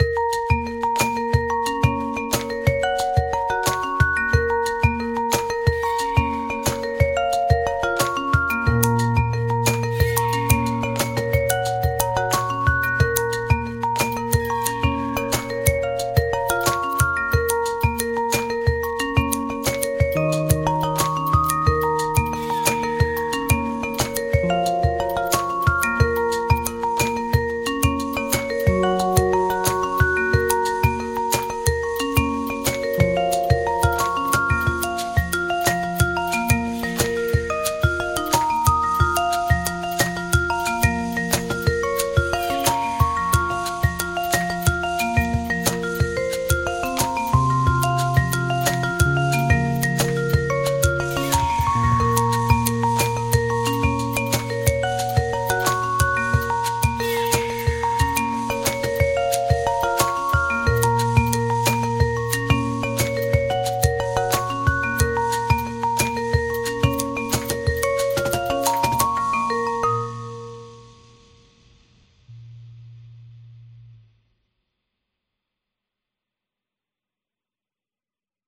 Oh and there’s scissors for percussion.